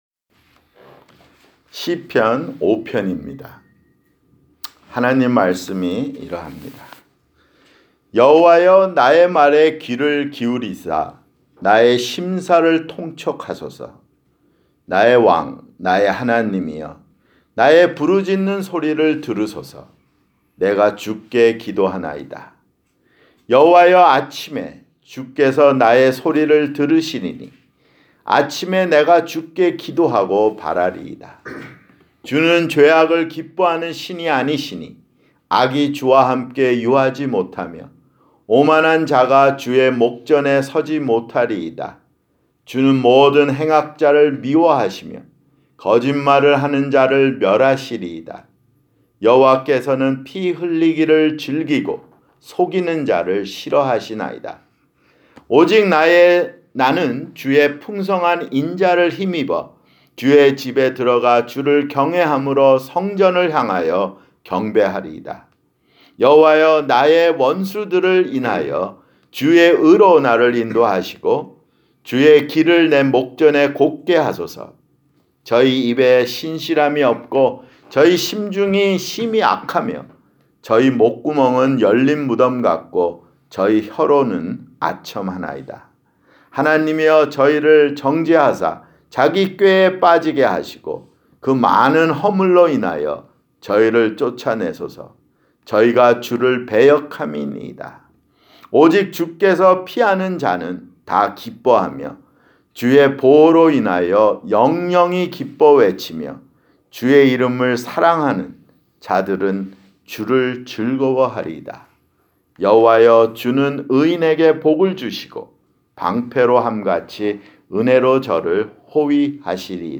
[주일설교] 시편 (6)